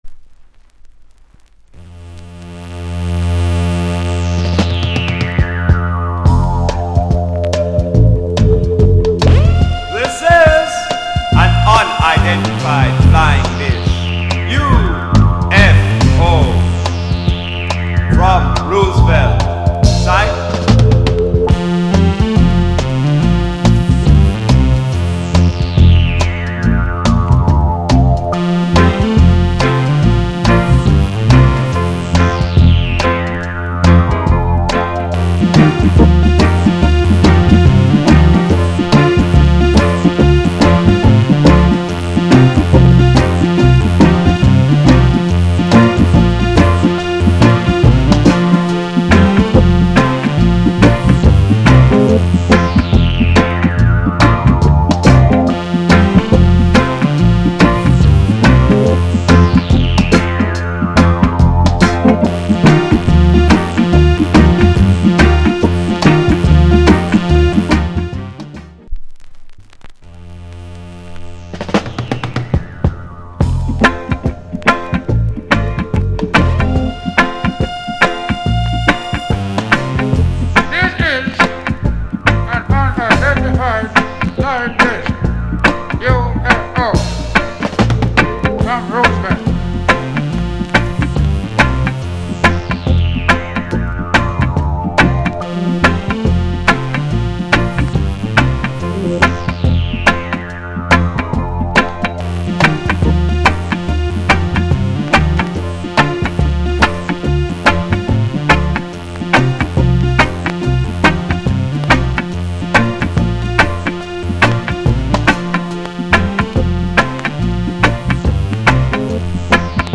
** B面傷によるパチノイズ。